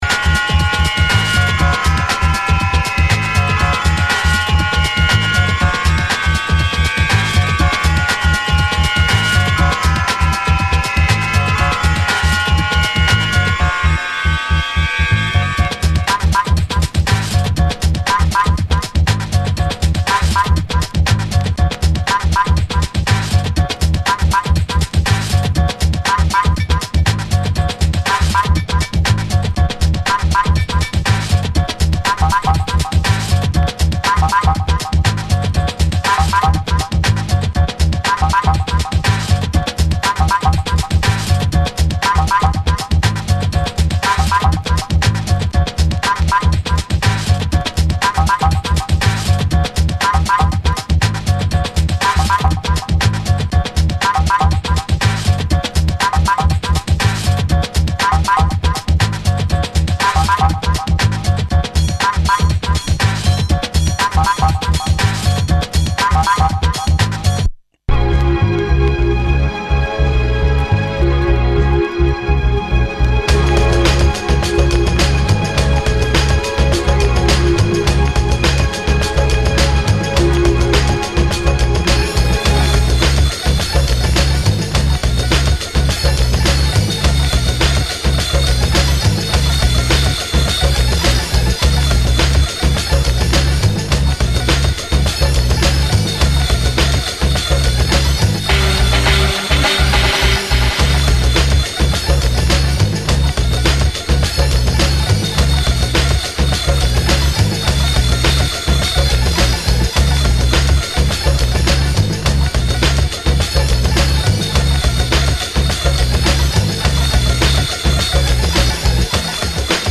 Top downtempo breaks.